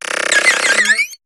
Cri de Rapion dans Pokémon HOME.